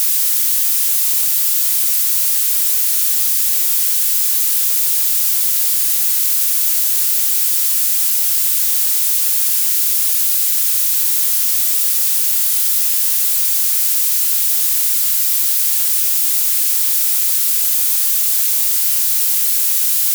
Generating violet noise
Generate white-noise, then apply this code to it via Nyquist prompt